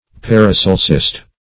Paracelsist \Par`a*cel"sist\